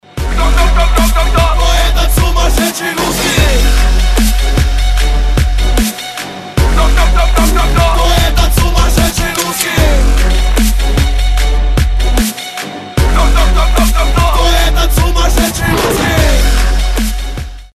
• Качество: 256, Stereo
Хип-хоп
Trap
Dubstep